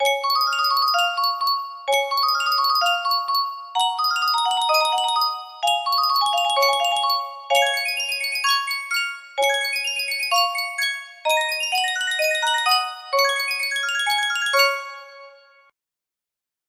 Sankyo Music Box - Tchaikovsky 1812 Overture DUQ music box melody
Full range 60